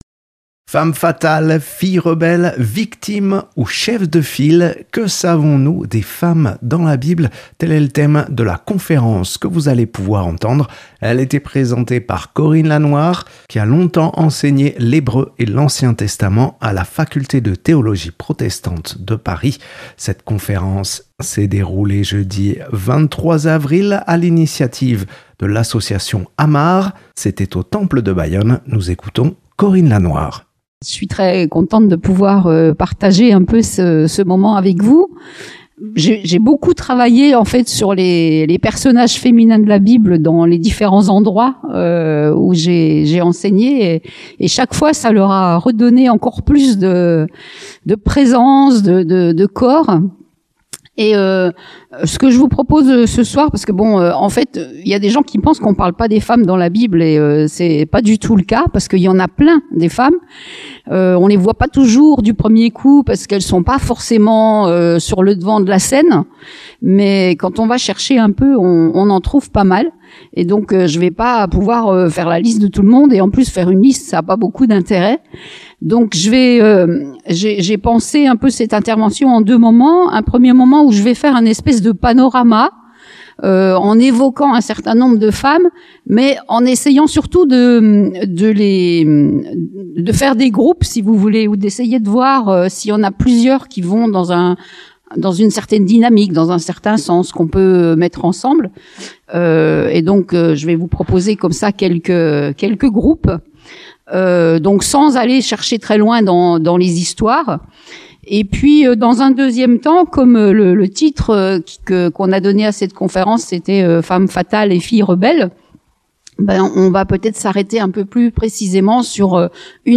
Enregistrée le 23 avril au Temple de Bayonne.
Accueil \ Emissions \ Magazines \ Culture \ Que savons-nous des femmes dans la bible ?